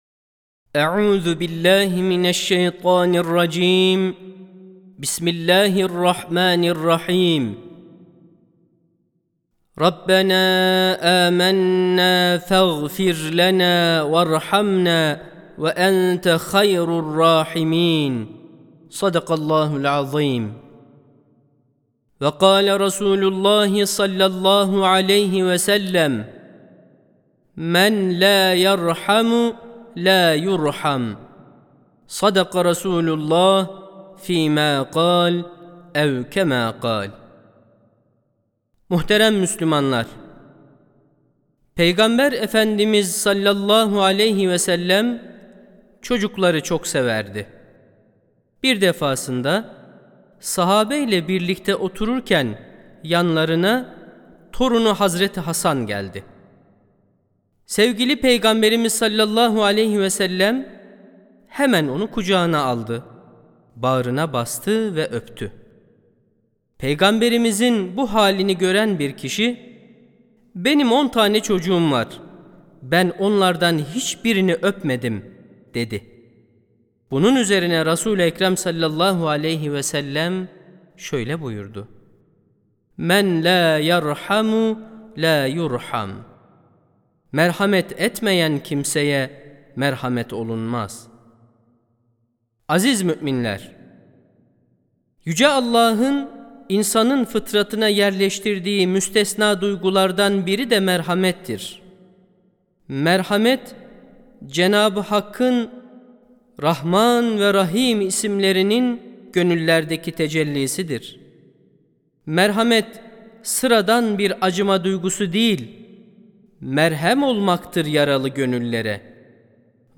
08.11.2024 Cuma Hutbesi: Merhamet Eden Merhamet Bulur (Sesli Hutbe, Türkçe, İngilizce, Rusça, İspanyolca, Almanca, Arapça, Fransızca, İtalyanca)
Sesli Hutbe (Merhamet Eden Merhamet Bulur).mp3